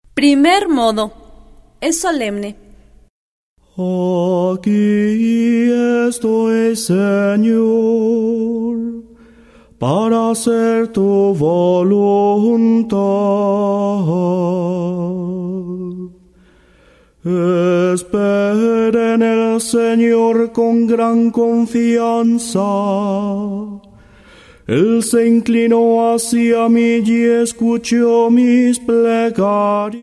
02 Primer modo gregoriano.